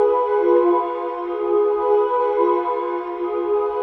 cch_synth_nasty_125_Bm.wav